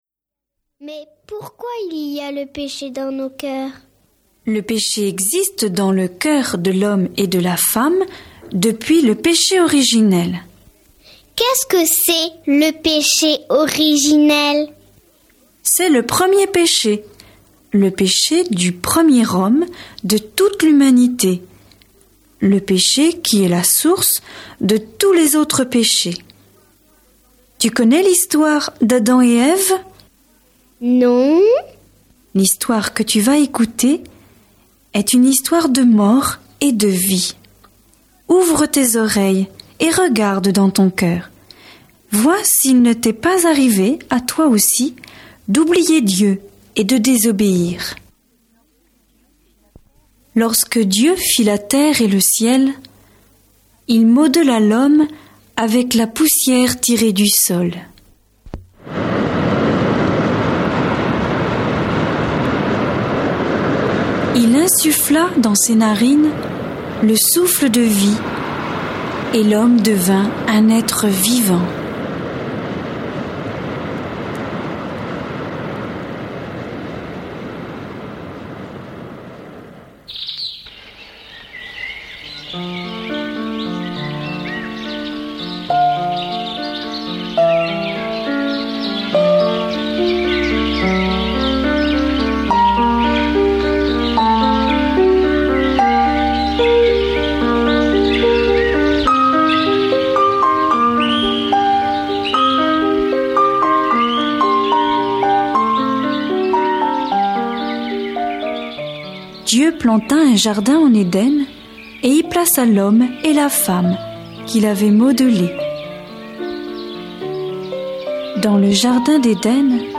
« Pourquoi il y a le péché dans nos cœurs ? » Une explication simple et claire, à travers un dialogue entre un enfant et un adulte, de ce qu’est le péché et l’amour de Dieu.
Une merveilleuse écoute avec musiques, bruitages, dialogues…